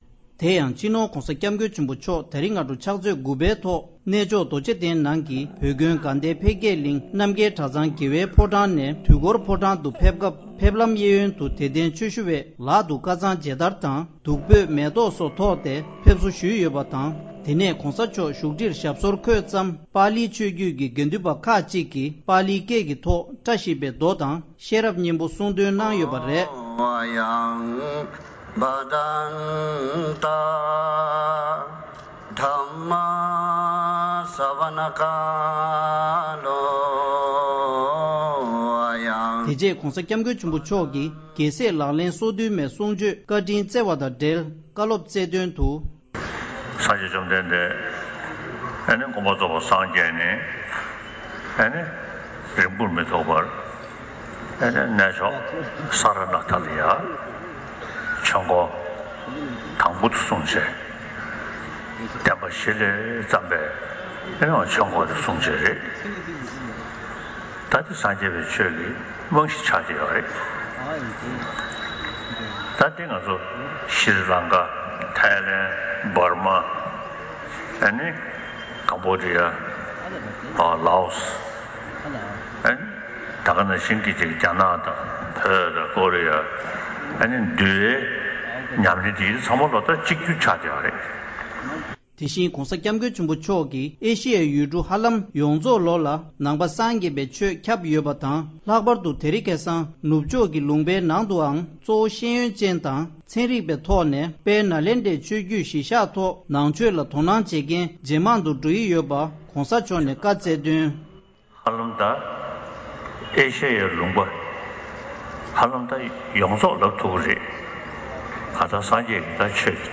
སྤྱི་ནོར་༧གོང་ས་སྐྱབས་མགོན་ཆེན་པོ་མཆོག་གིས་གནས་མཆོག་རྡོ་རྗེ་གདན་དུ་དད་ལྡན་ཆོས་ཞུ་བ་ཁྲི་ཚོ་ཁ་ཤས་ལ་རྒྱལ་སྲས་ལག་ལེན་སོ་བདུན་མའི་ཆོས་འབྲེལ་དབུ་འཛུགས་མཛད་སོང་།